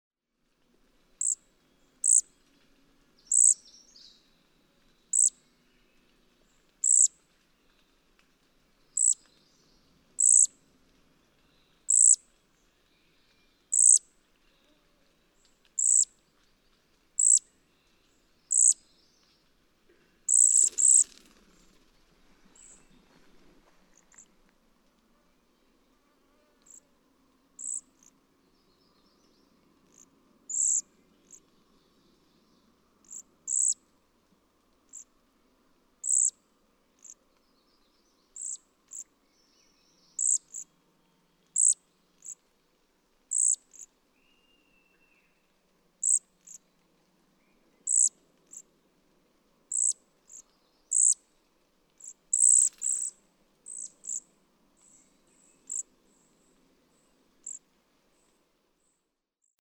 Cedar waxwing
♫445. The bzee note, used by a female when demanding food from her mate (or, in older language, "begging for" food from her mate).
Westmanland, Maine.
445_Cedar_Waxwing.mp3